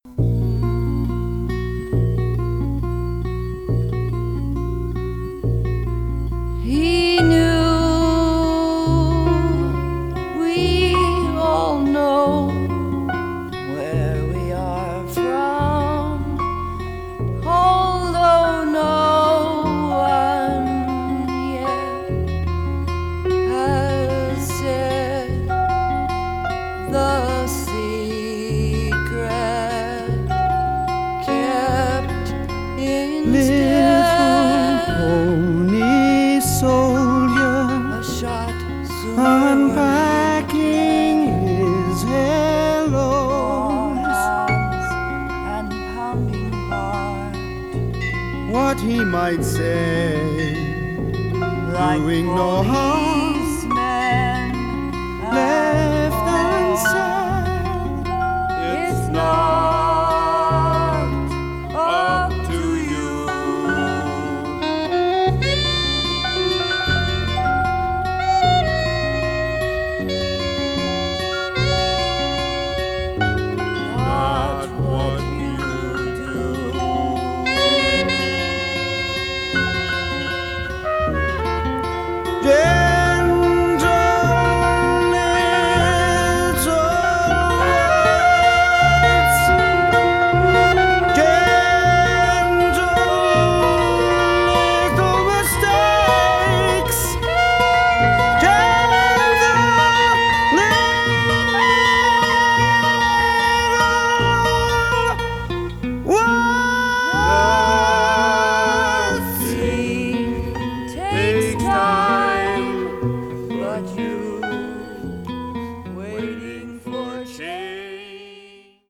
acoustic guitar
alto sax
trumpet